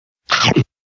Fx Mordisco Sound Button - Free Download & Play